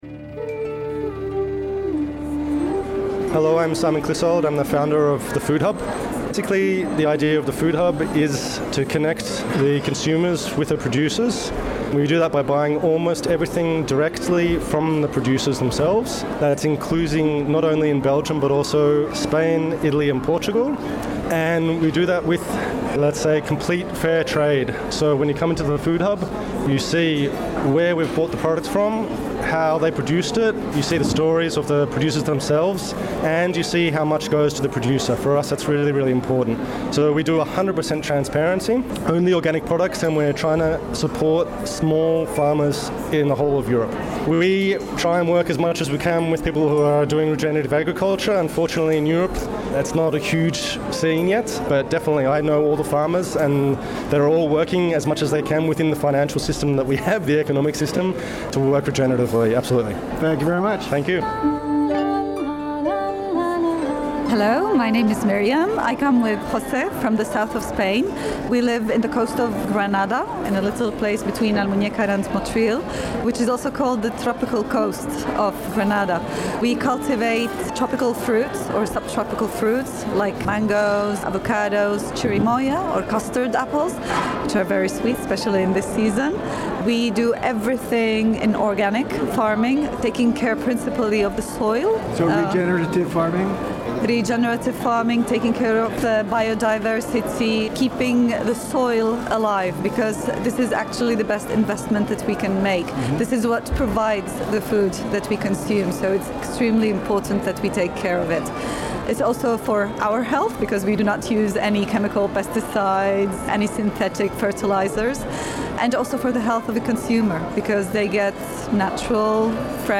two Spanish producers and one Italian producer.